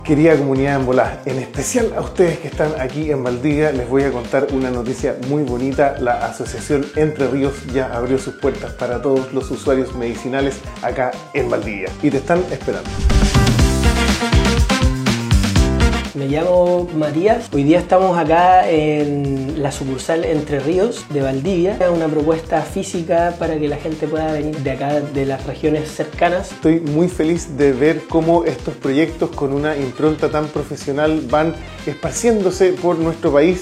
Utilizando las redes sociales, invitaban a la comunidad a adquirir sus productos, tal como lo hace cualquier local comercial legalmente establecido. Este es uno de sus spots publicitarios.